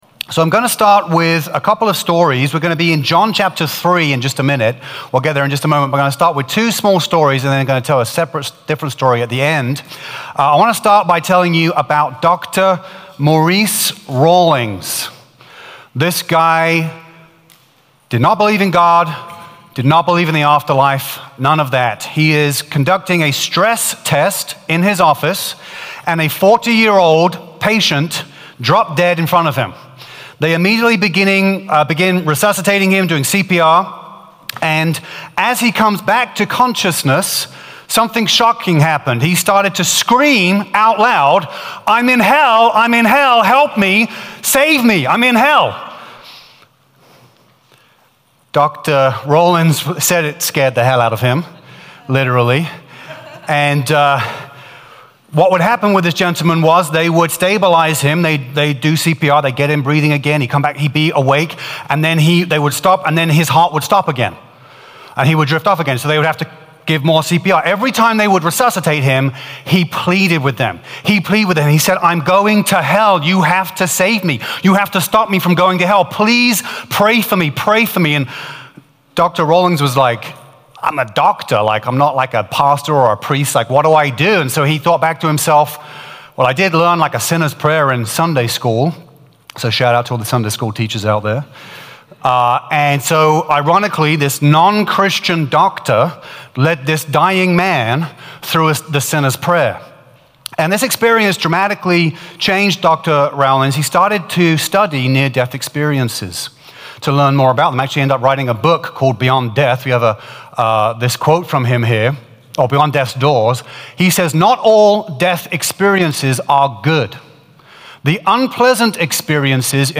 A message from the series "Heaven's Mysteries."